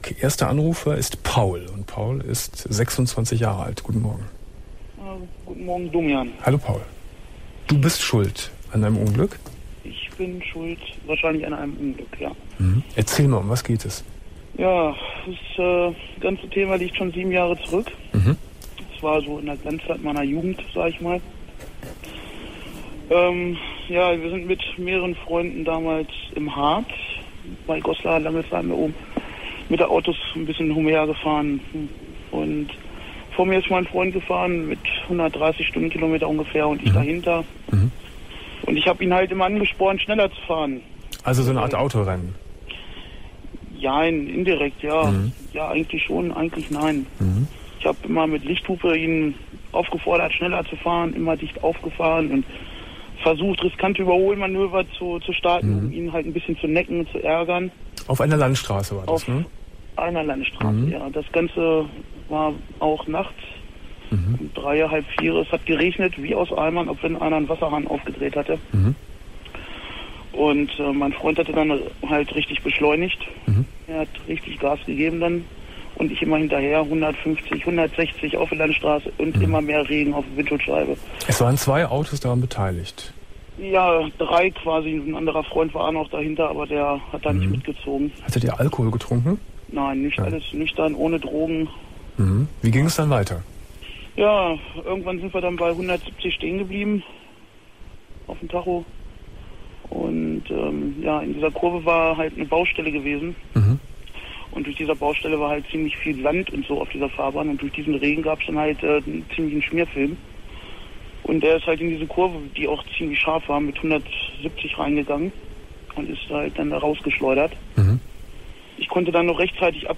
07.09.2001 Domian Thema: Ich bin Schuld an einem Unglück ~ Domian Talkradio Archiv Podcast